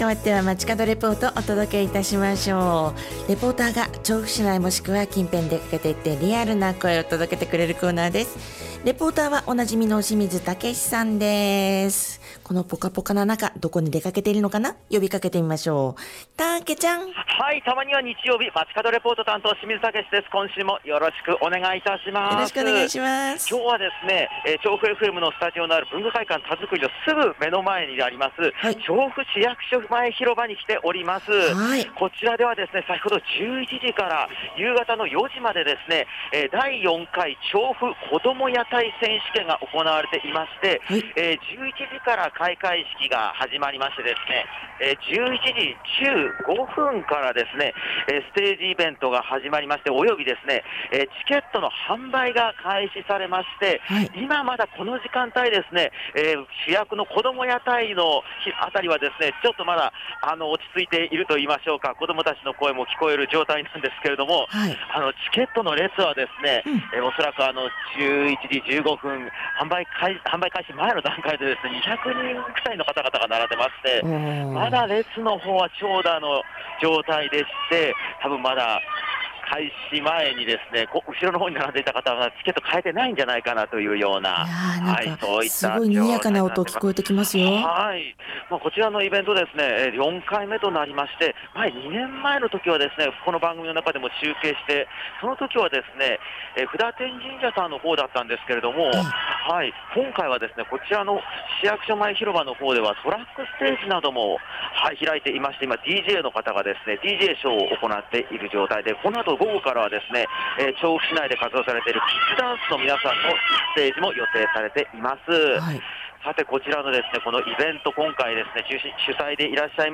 すっかり春の暖かい日曜日にお届けした本日の街角レポートは、 調布市役所前広場で11時～16時の時間帯に開催されている『第4回調布こども屋台選手権』の会場からレポートです！